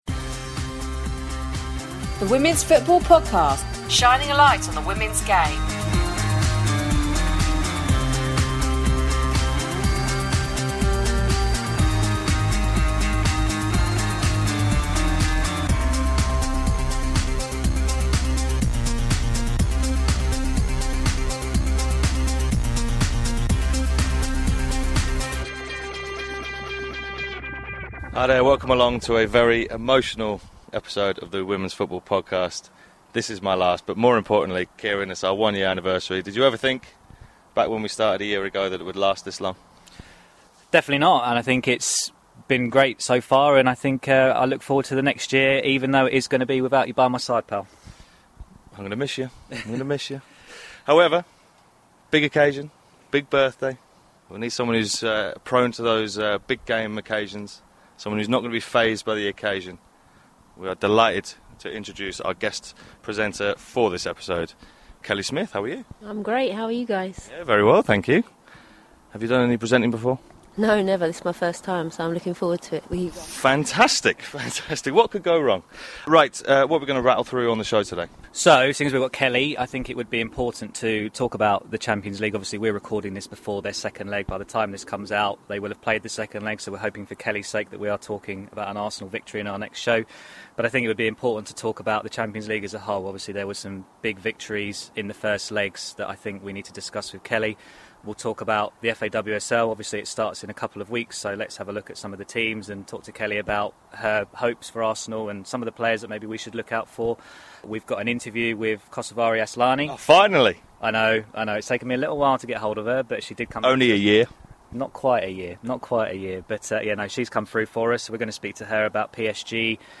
Who better to draft in then as a guest presenter than Arsenal Ladies & England legend Kelly Smith! Kelly and the lads discuss all things UEFA Women’s Champions League (recorded before her second leg defeat), FAWSL, NWSL and her future plans.